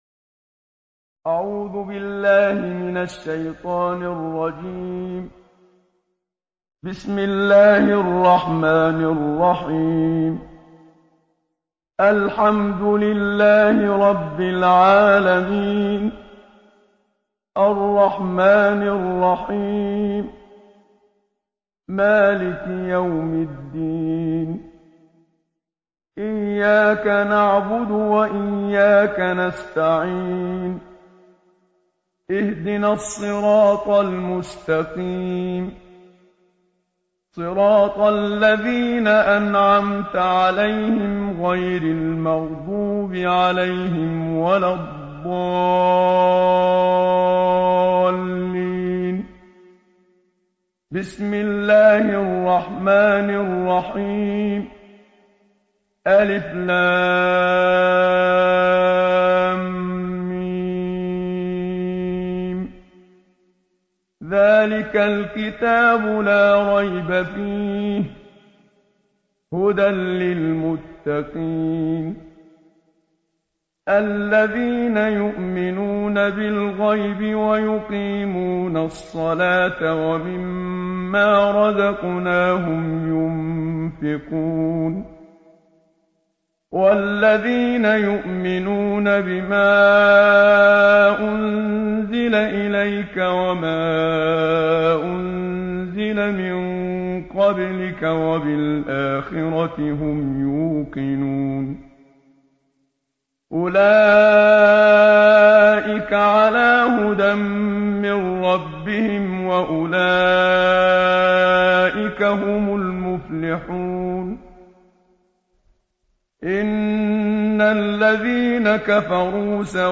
ختمة مرتلة لأجزاء القرآن الكريم.. الشيخ محمد صديق المنشاوي
القرآن الكريم - الكوثر: ترتيل أجزاء القرآن الكريم كاملة بصوت القارئ الشيخ محمد صديق المنشاوي.